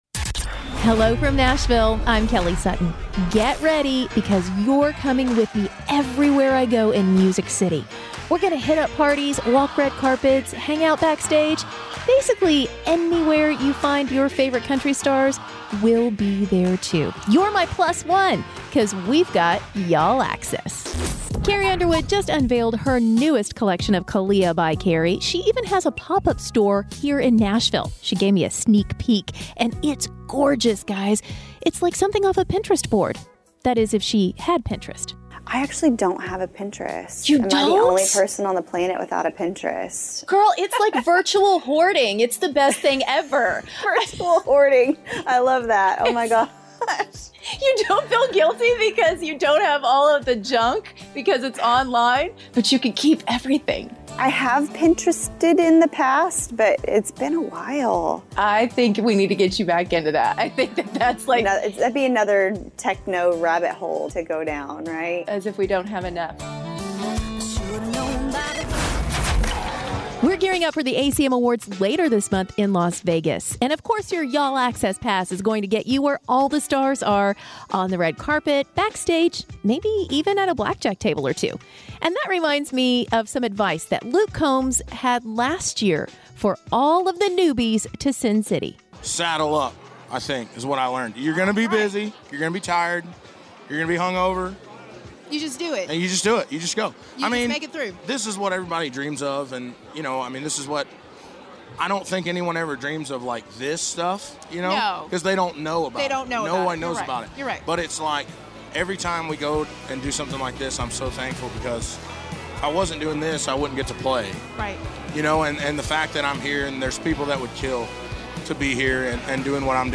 The show is produced out of the Silverfish studios in Nashville, TN.